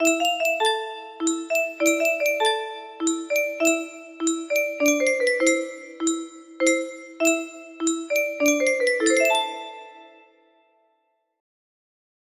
A music box